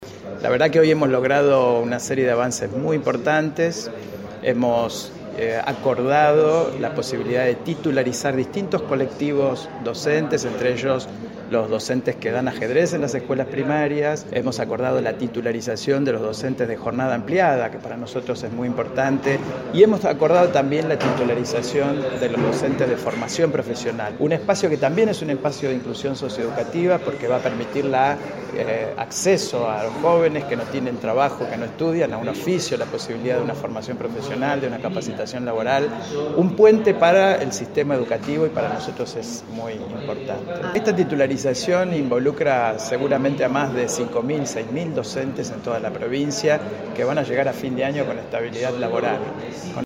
El secretario de la cartera educativa, Oscar Di Paolo celebró en Radio EME el gran acuerdo celebrado con la dirigencia docente.